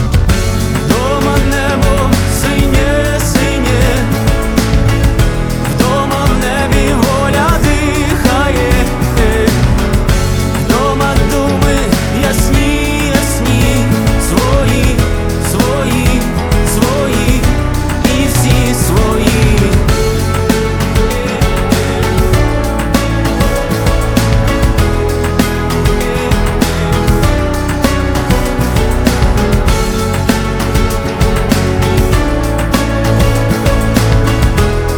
Жанр: Русская поп-музыка / Рок / Русский рок / Русские